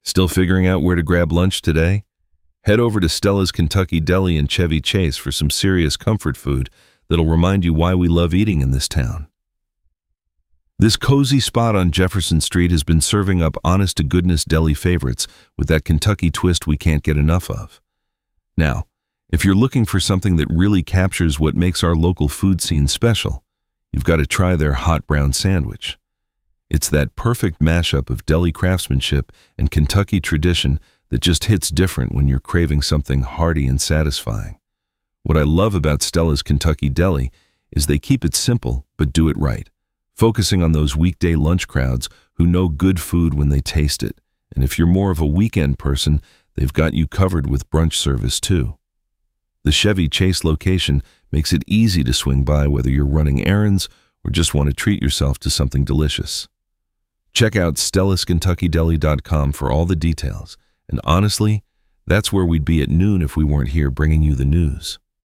Free, AI-powered local news for Lexington, Kentucky
Voice synthesis via ElevenLabs; script via Claude.